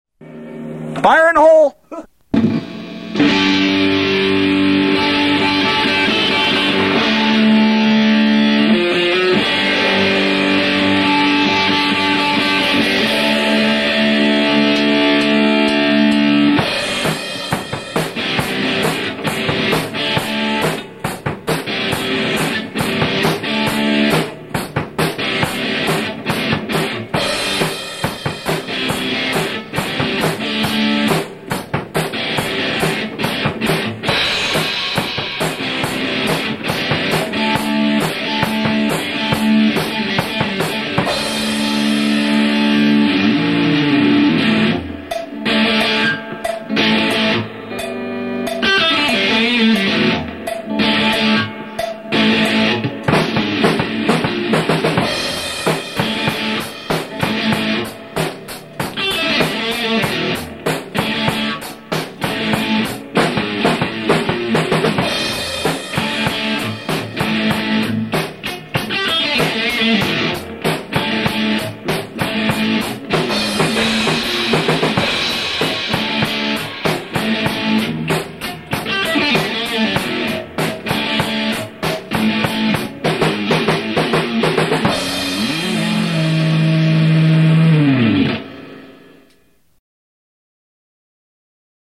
They are completely and unapologetically live.